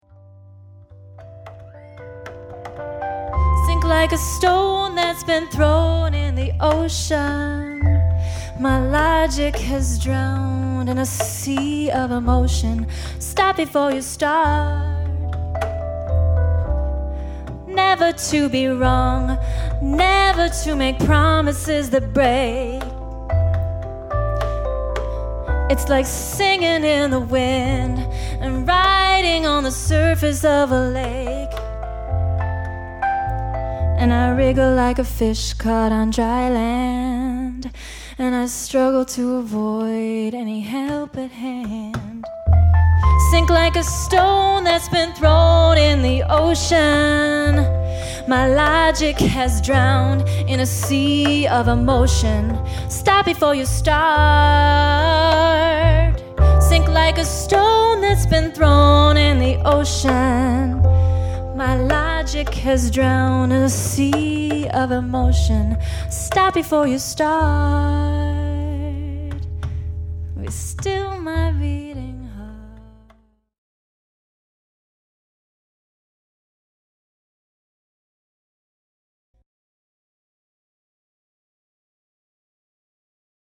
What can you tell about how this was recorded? [Live]